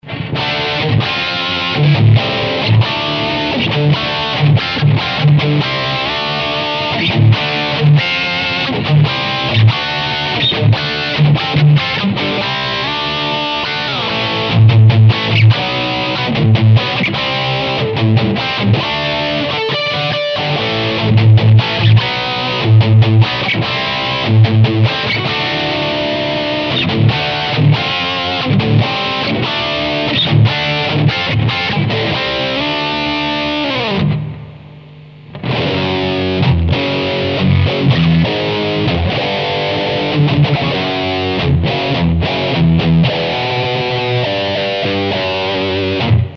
Zu hören sind meine Gold Top (mit Duncan Seth Lover PUs), der Demonizer und eine Prise Hall damit es nicht so knochentrocken ist:
Demonizer - Angry
Ich habe immer mehr oder weniger dasselbe gespielt, so kann man die Sounds direkt vergleichen, natürlich würde jemand in einer Industrial-Band bestimmt ganz anders spielen, aber ich spiele halt in keiner Industrial-Band. :-)